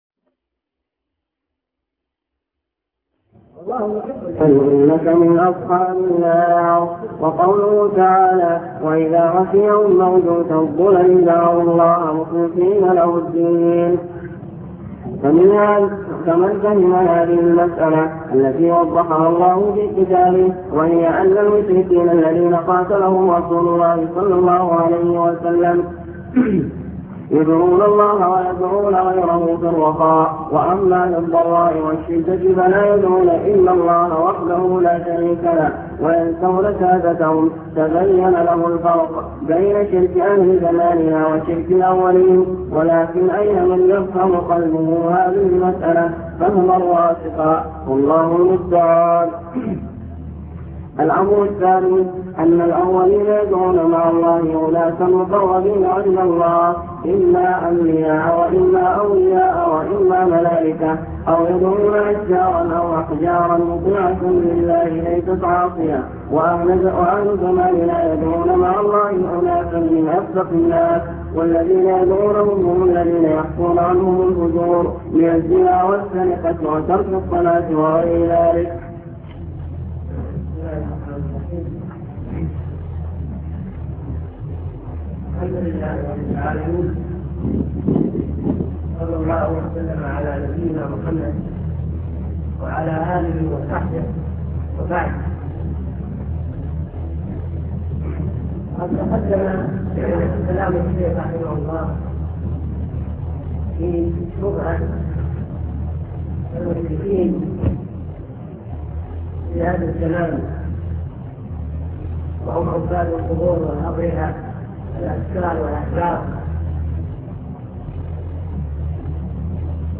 الدرس السادس